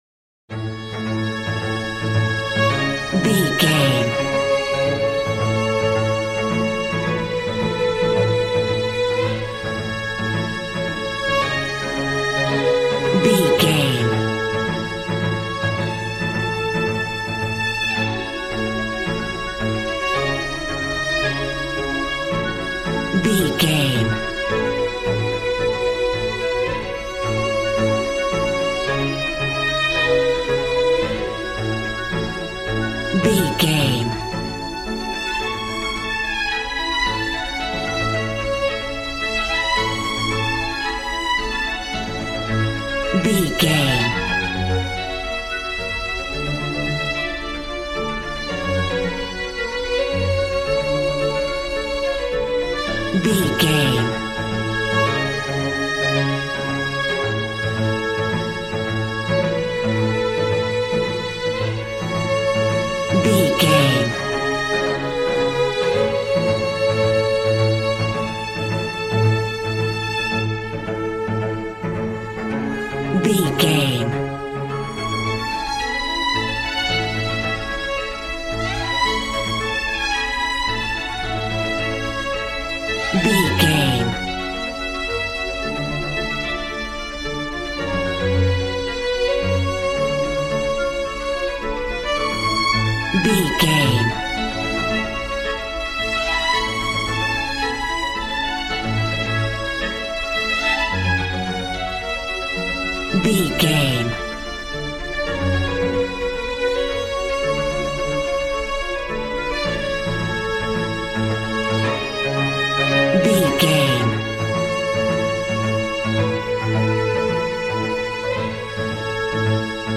Ionian/Major
regal
cello
violin
brass